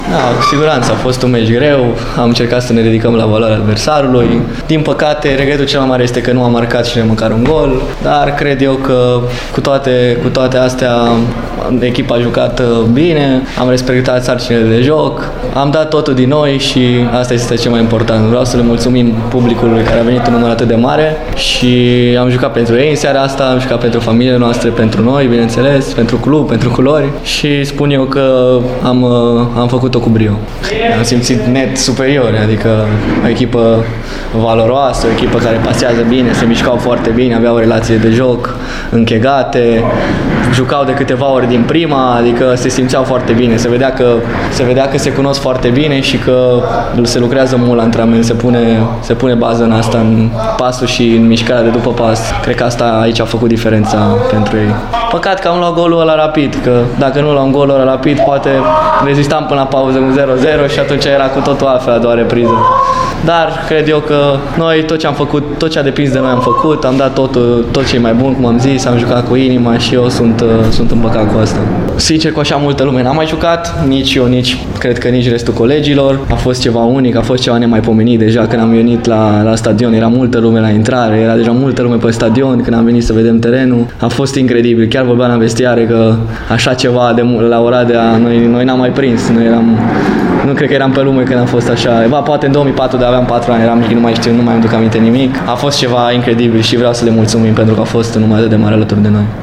a vorbit, la finalul jocului, despre seara specială de la Oradea: